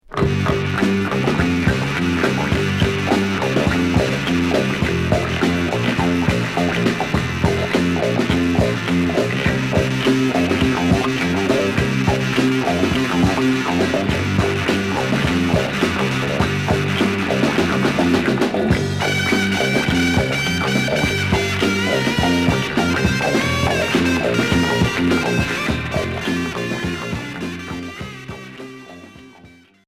Heavy pop psychédélique